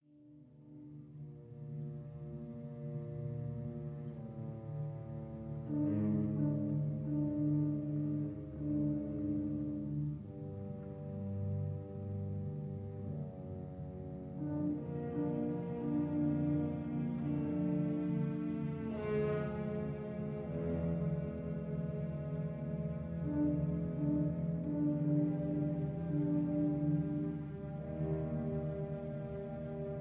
This is a stereo recording